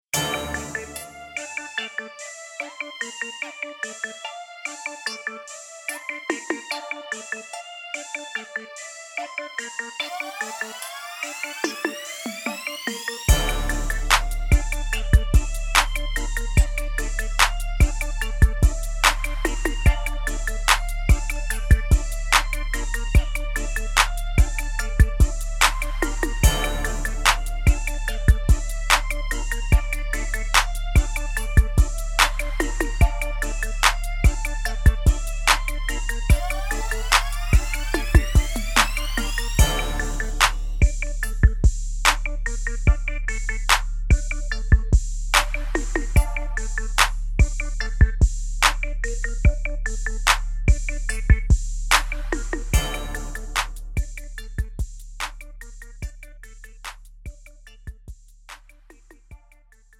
장르 pop 구분 Premium MR
Premium MR은 프로 무대, 웨딩, 이벤트에 최적화된 고급 반주입니다.